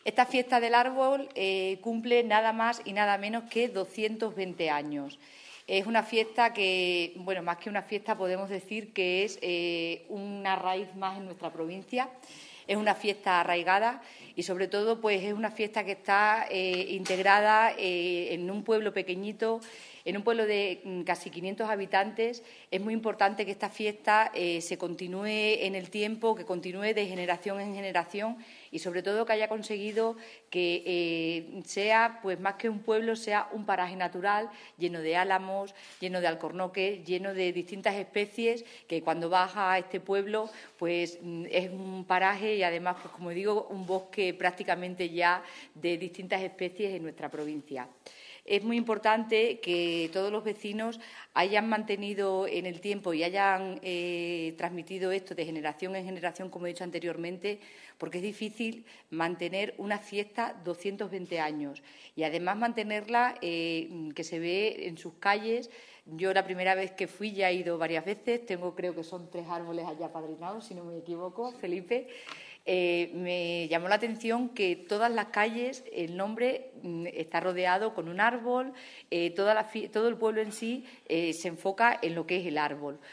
CORTES DE VOZ
Fiesta del Árbol_Elísabeth Martín_Diputada de Turismo y Juventud
Elisabeth-Martin_Presentacion-Fiesta-del-Arbol.mp3